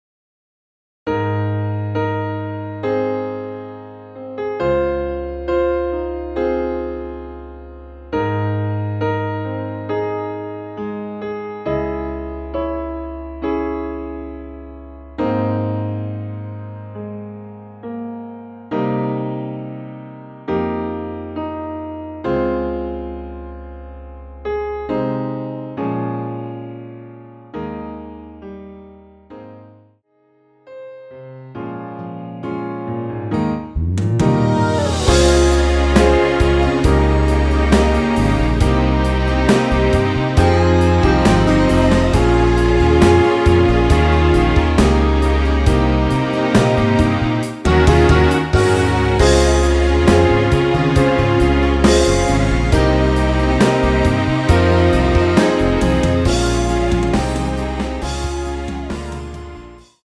원키에서(+2)올린 MR입니다.
Ab
앞부분30초, 뒷부분30초씩 편집해서 올려 드리고 있습니다.